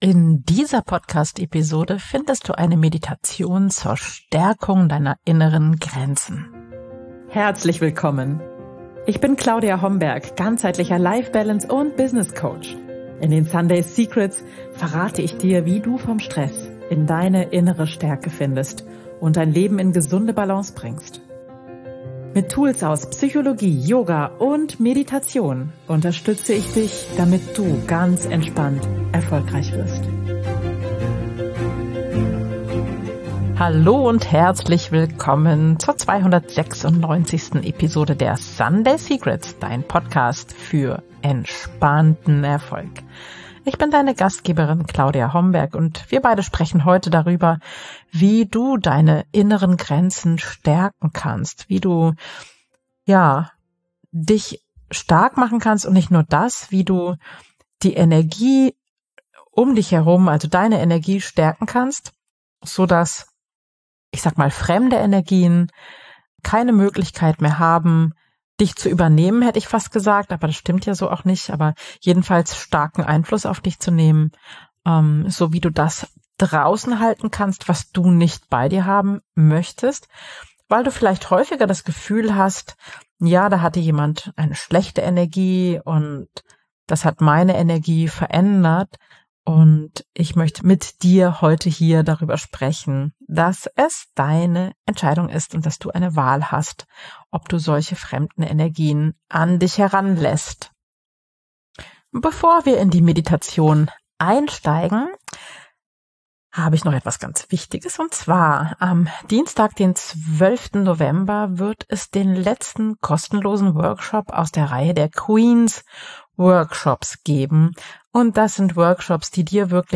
Energievampire adé: Meditation zur Stärkung Deiner Aura ~ Sunday Secrets – Midlife, Wandel & Selbstbestimmung Podcast